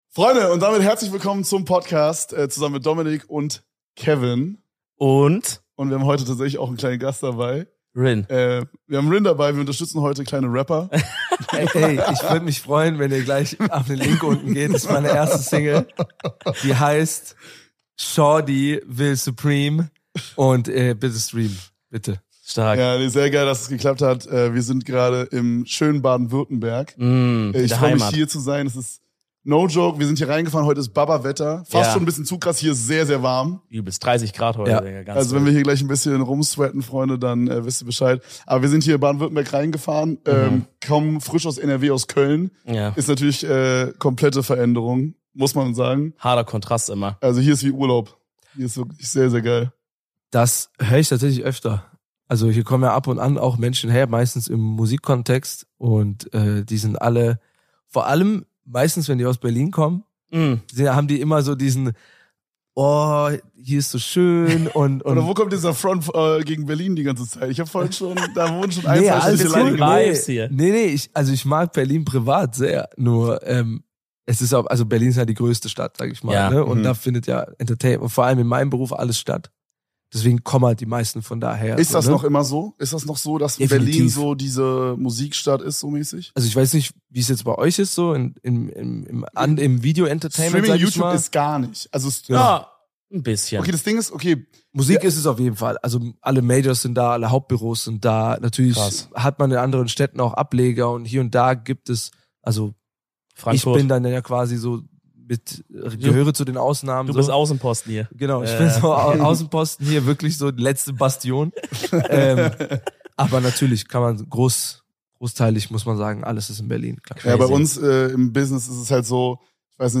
Das RIN Interview